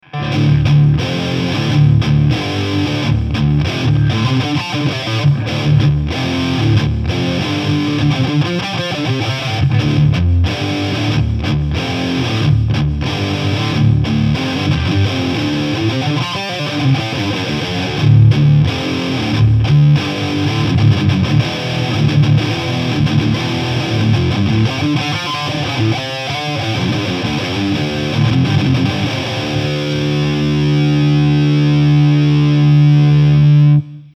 Heavy Rhythm 1 sample Straight of mic'ed Vintage 30 Cab. Channel 3